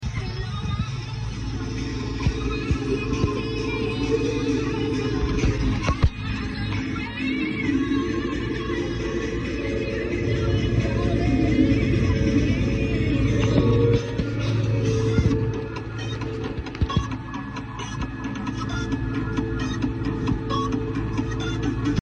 That sky, tractor and tunes sound effects free download